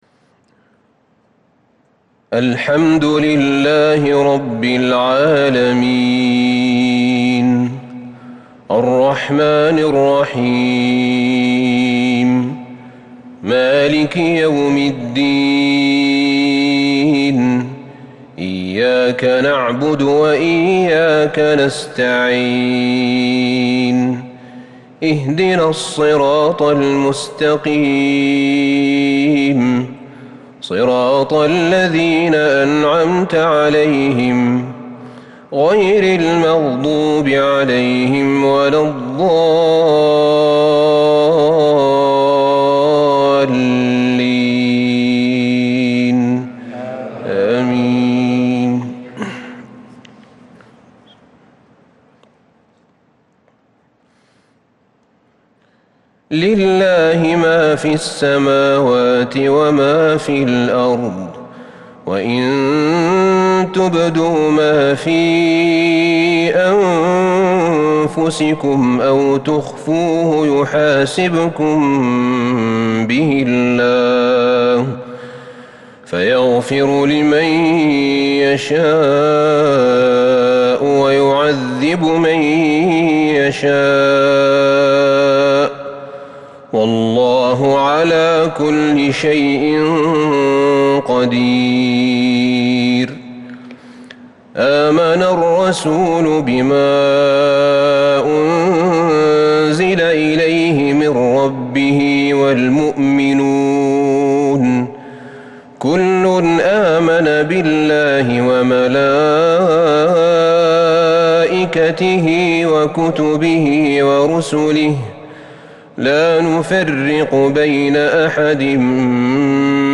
عشاء الاربعاء 28 شوال 1442هـ أواخر سورتي البقرة وآل عمران |  Isha prayer from Surah Al-Baqarah& Al Imran 9/6/2020 > 1442 🕌 > الفروض - تلاوات الحرمين